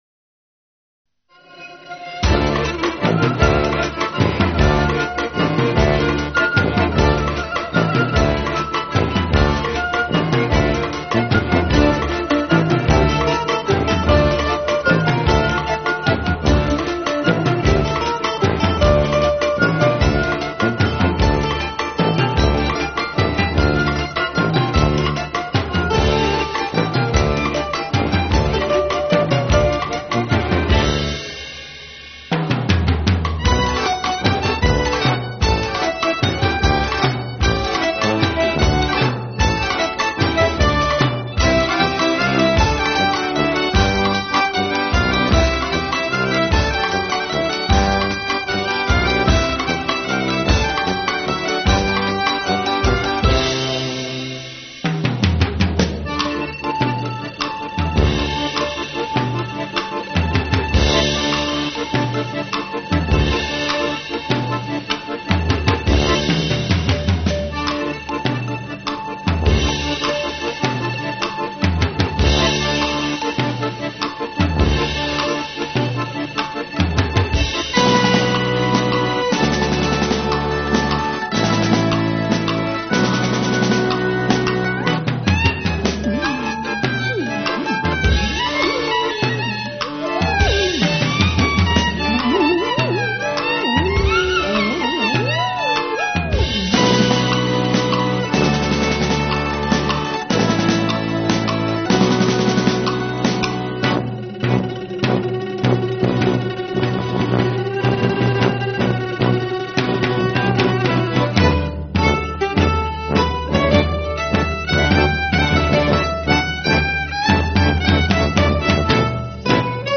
Batterie et percussions
tuba, voix
violon, accordéon